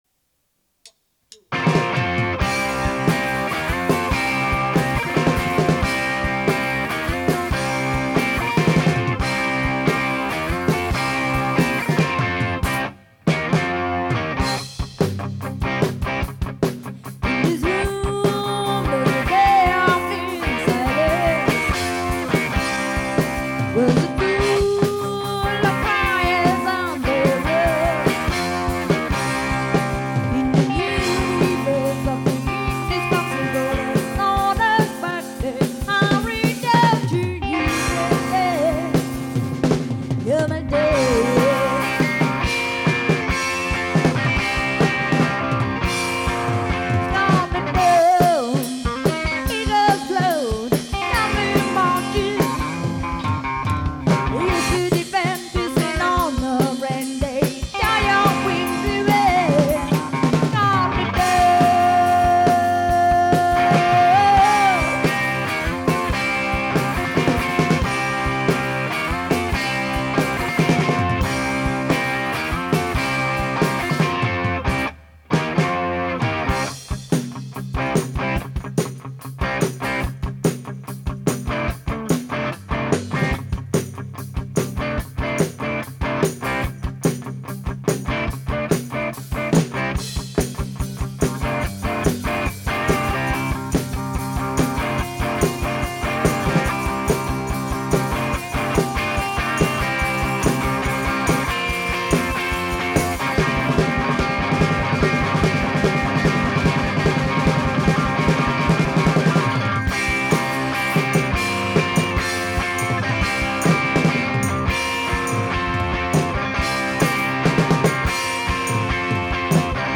Luogo esecuzioneSala prove Modena